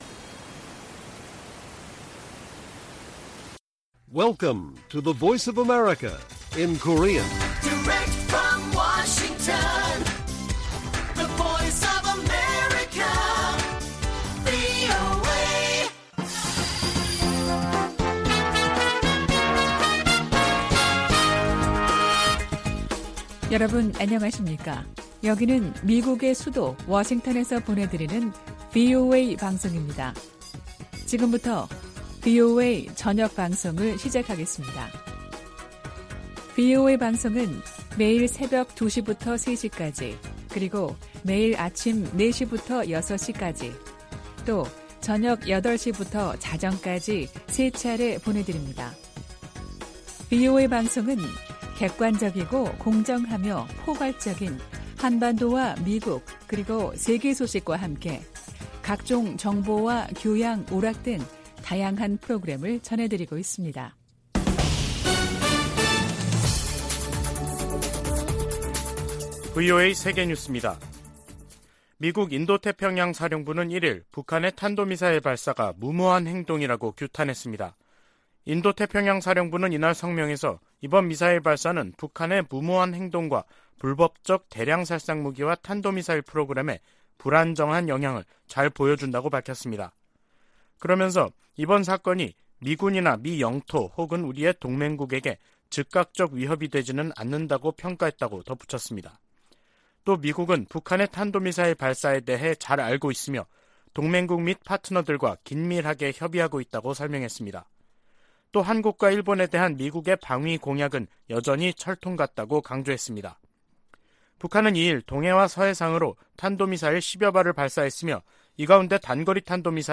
VOA 한국어 간판 뉴스 프로그램 '뉴스 투데이', 2022년 11월 2일 1부 방송입니다. 북한 정권이 남북 분단 이후 처음으로 동해 북방한계선 NLL 이남 한국 영해 근처로 탄도미사일을 발사하는 등 미사일 수십 발과 포병 사격 도발을 감행했습니다. 윤석열 한국 대통령은 ‘실질적 영토침해 행위”라며 엄정한 대응을 지시했고 공군기를 출동시켜 공해상에 미사일 사격을 했습니다.